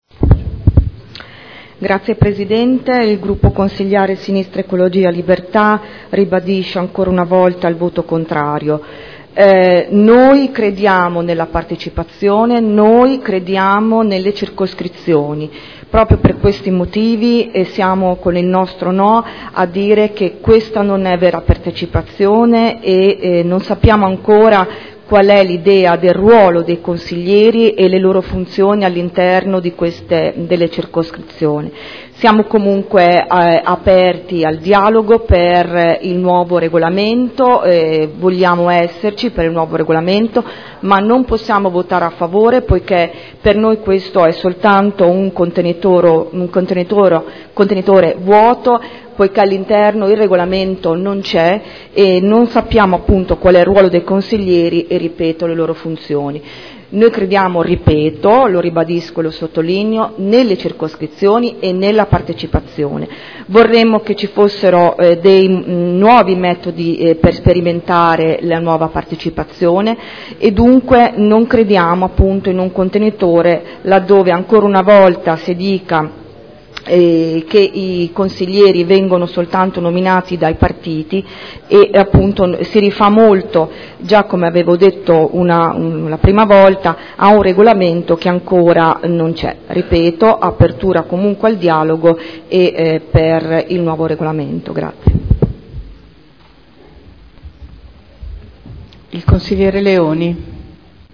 Seduta del 24 febbraio.Proposta di deliberazione: Proposta modifiche allo Statuto comunale – Approvazione. Dibattito